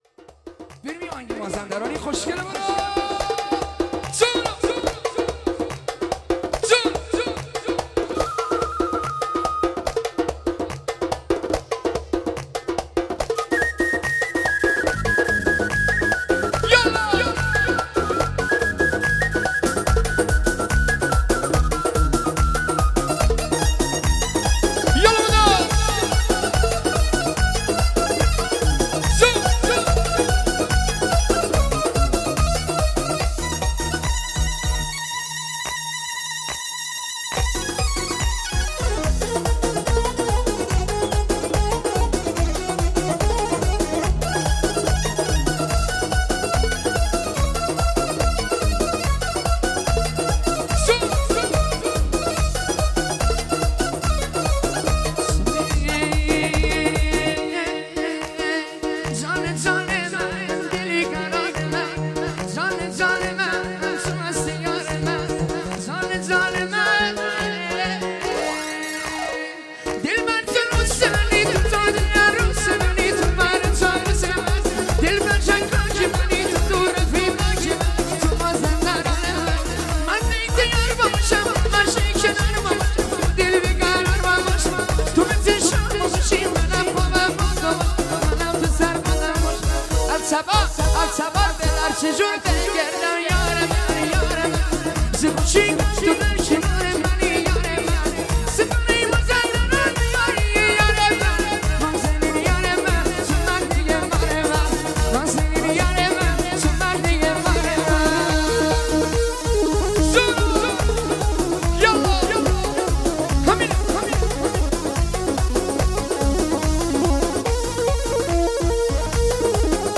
آهنگ ارکستی شاد